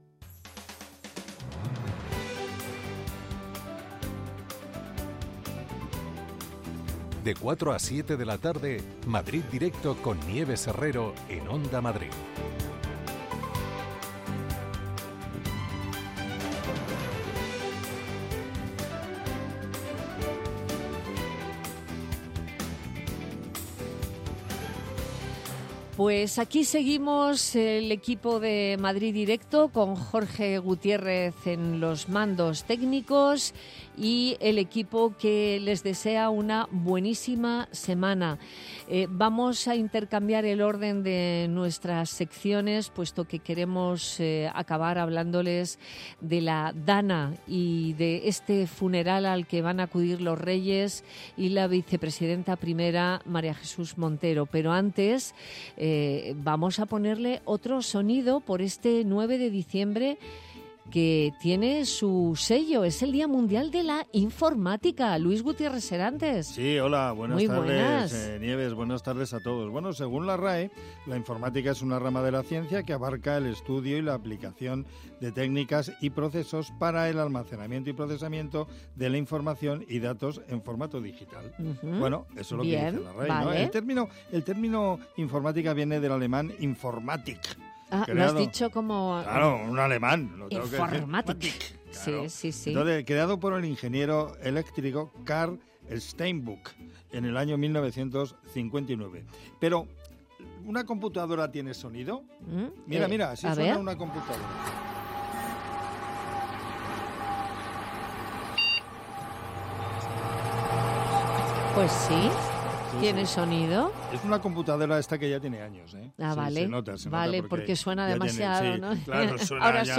Nieves Herrero se pone al frente de un equipo de periodistas y colaboradores para tomarle el pulso a las tardes. Tres horas de radio donde todo tiene cabida. La primera hora está dedicada al análisis de la actualidad en clave de tertulia. La segunda hora está dedicada a la cultura en Madrid.